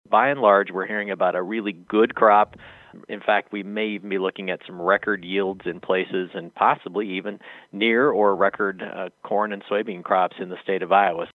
IOWA SECRETARY OF AGRICULTURE MIKE NAIG SAYS THE END OF THE DROUGHT HAS EXPECTATIONS HIGH, AND THOSE AREAS SEEING WEATHER IMPACT DON’T BRING DOWN THE LOFTY HARVEST PROJECTIONS: